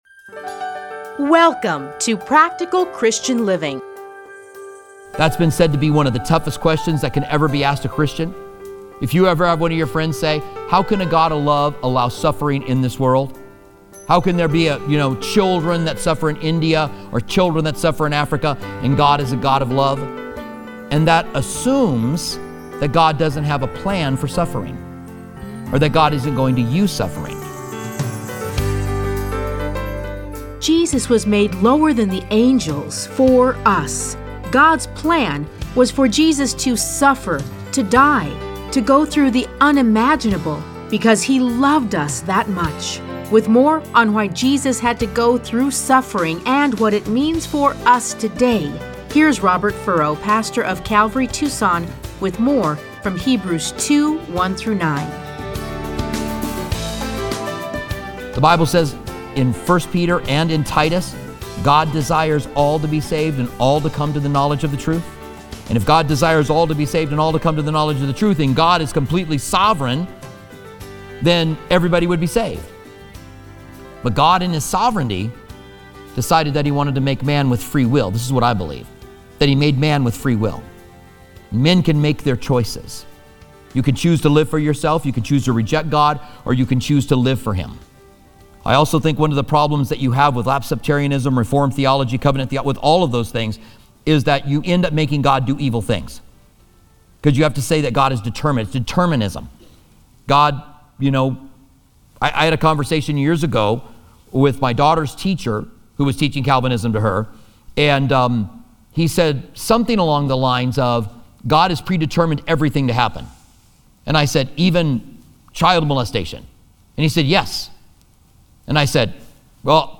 Listen to a teaching from Hebrews 2:1-9.